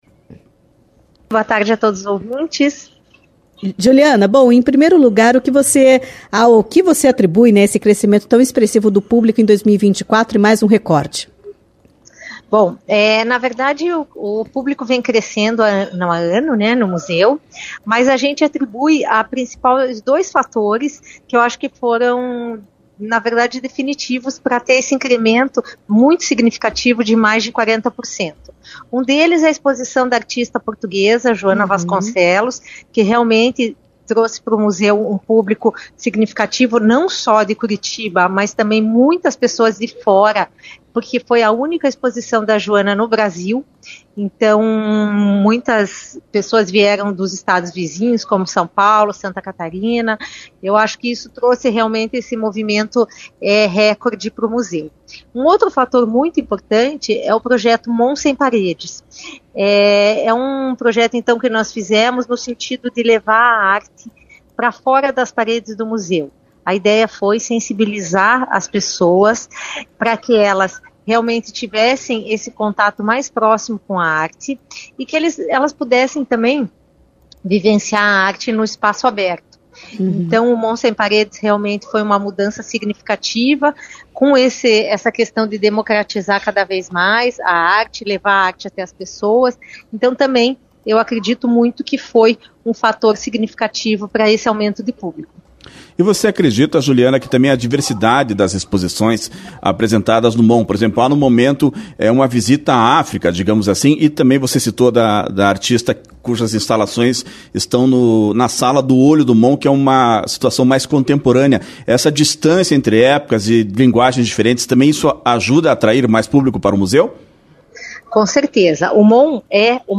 0801-Entrevista-MON.mp3